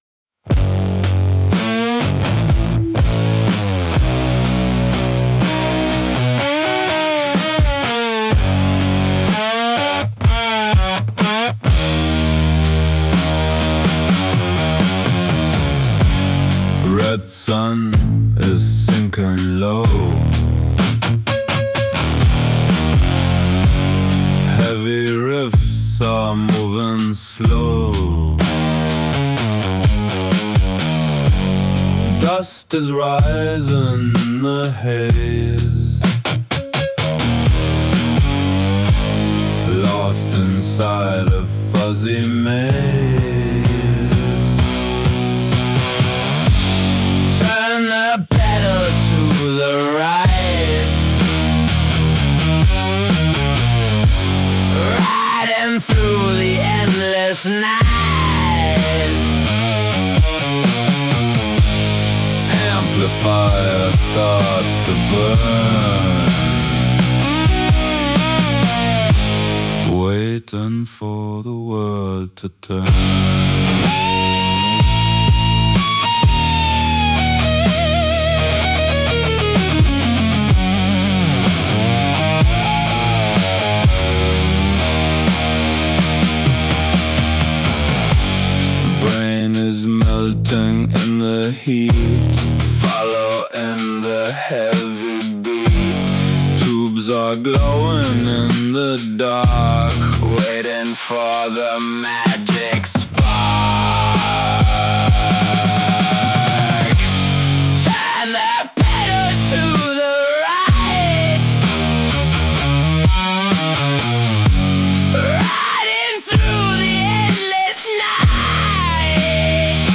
Hab das getestet und sogar den deutschen Akzent bekommt Suno hin.
raw home recording, unpolished lo-fi production, muddy mix, heavy down-tuned distorted guitars, slow fuzzy riffs, gritty tape saturation, heavy bass groove, amateur basement recording, english lyrics sung with heavy german accent
genau das meine ich, dieses unscharfe klanggebilde.
dann ist es so unscharf und verwaschen.